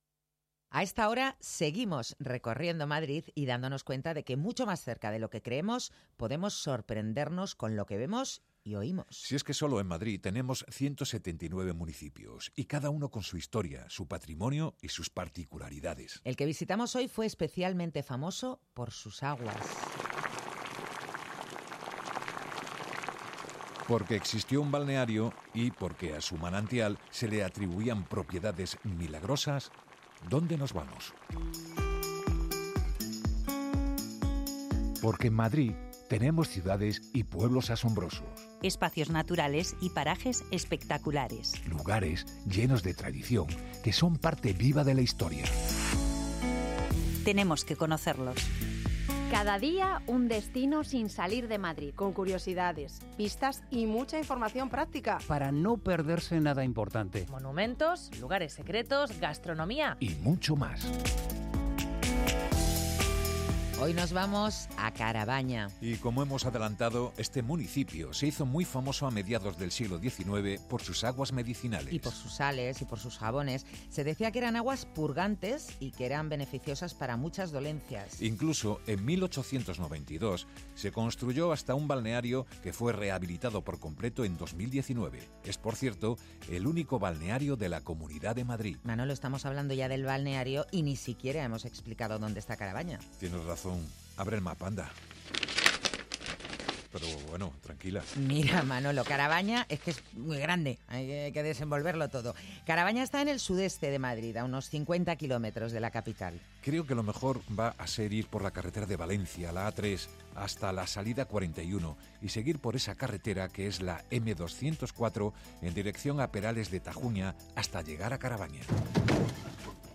Dos horas de radio, desde la medianoche hasta las dos de la madrugada.
Dos hasta las dos es el magazine nocturno de Onda Madrid.